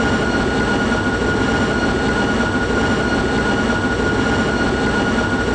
v2500-lowspool.wav